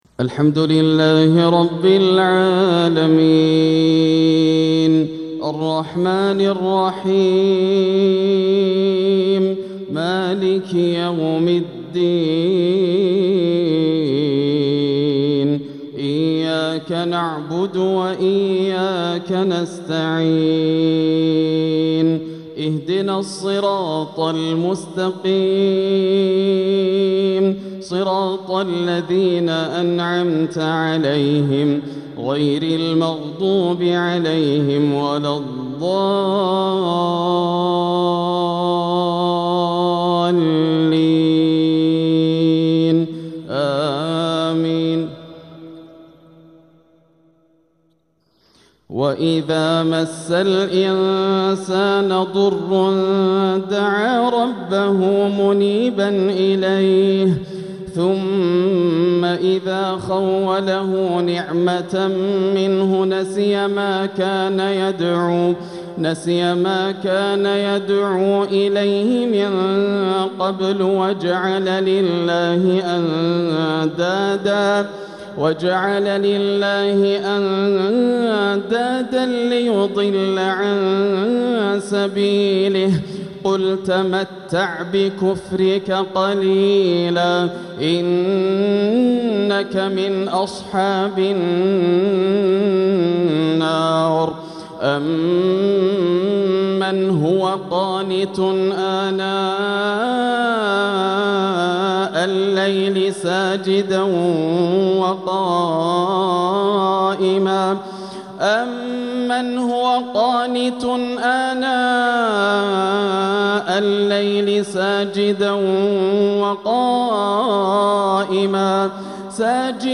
ضج الحرم بالبكاء وتأثر الشيخ بترتيل عذب يهز القلوب من سورة الزمر | صلاة العشاء – ١٢ ربيع الآخر ١٤٤٧ هـ > عام 1447 > الفروض - تلاوات ياسر الدوسري